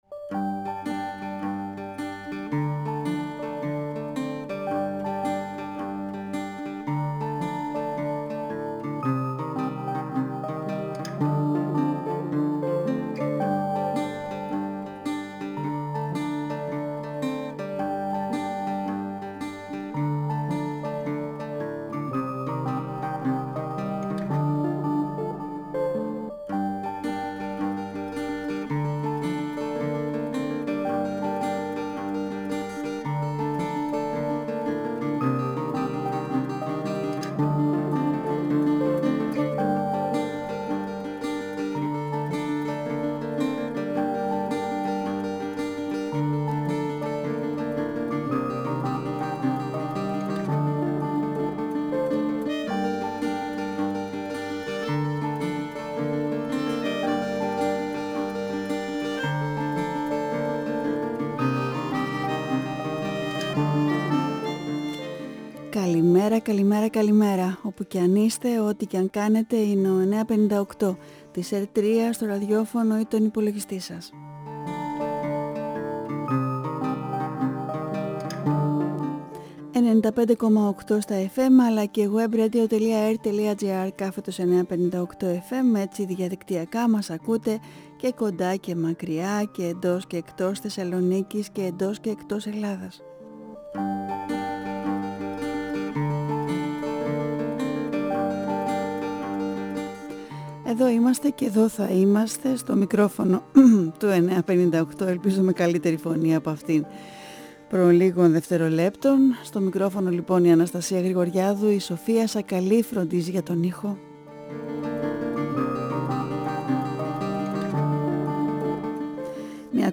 Μελοποιημένα ποιήματα του Κώστα Ουράνη στην εκπομπή Καλημέρα του 958fm – 12 Ιουλίου 2022